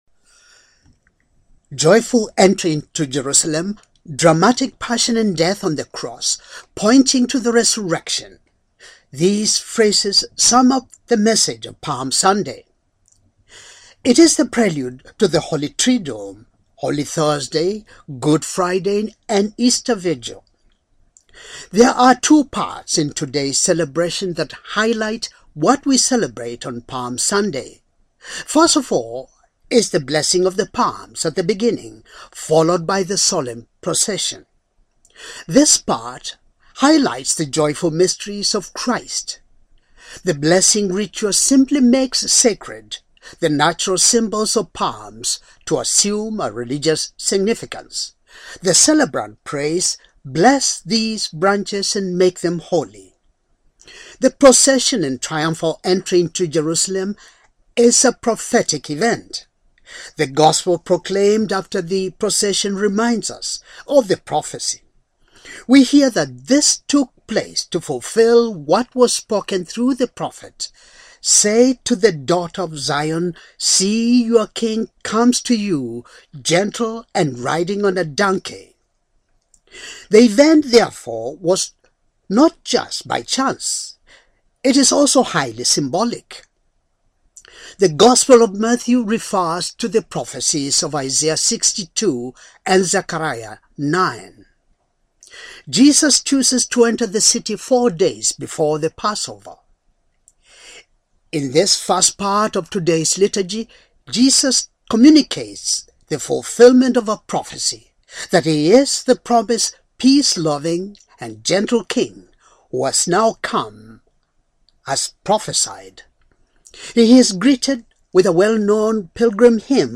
homily, Palm, Sunday, year, a,